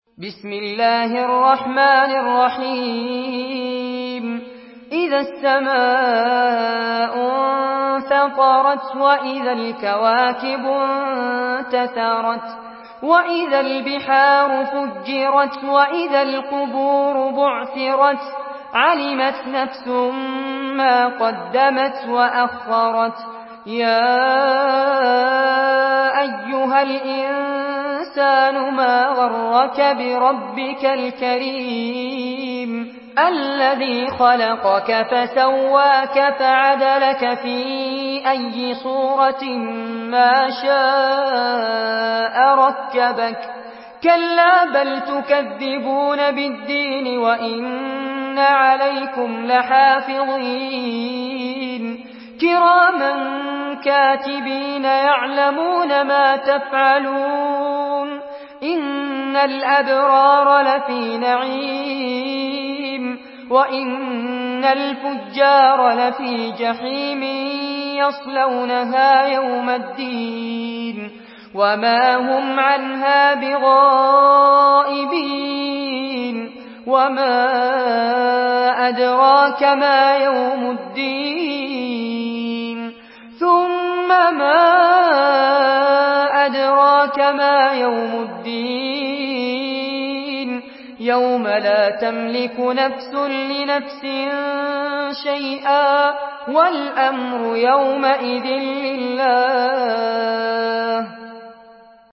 Surah Infitar MP3 by Fares Abbad in Hafs An Asim narration.
Murattal